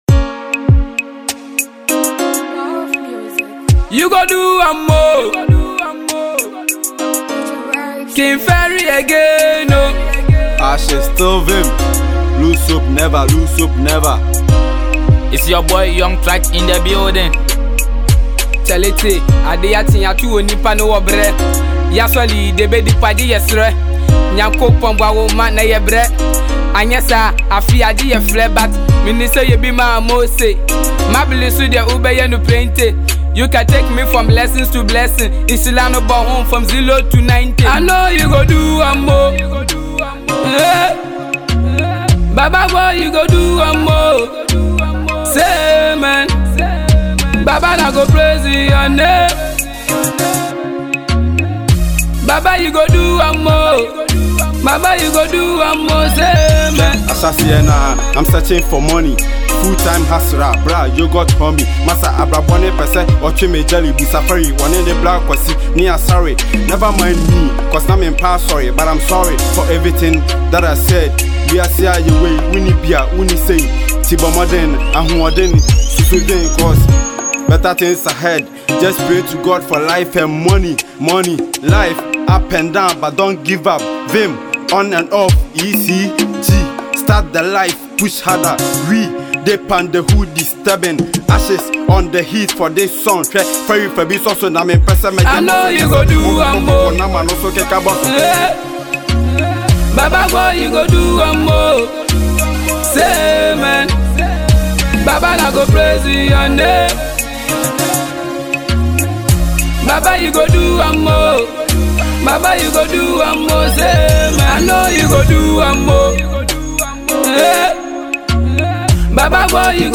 inspirational song